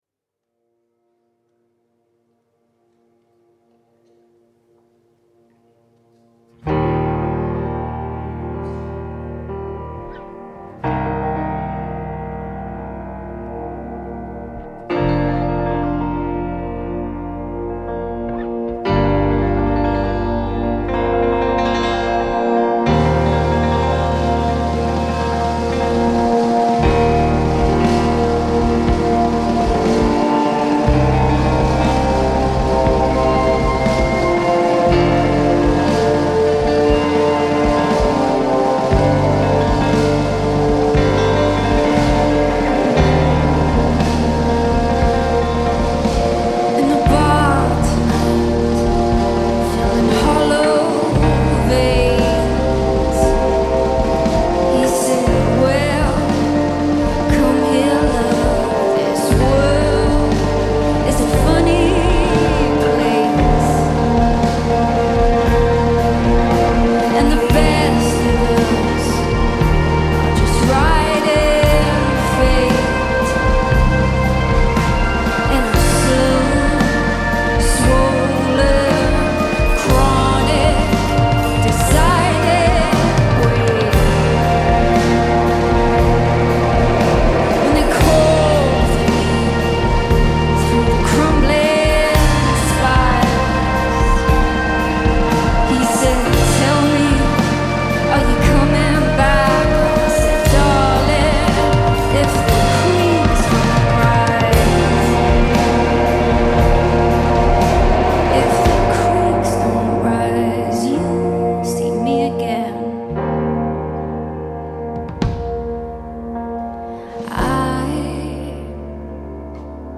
Newcastle Upon Tyne band
recorded live at Sage, Gateshead in February this year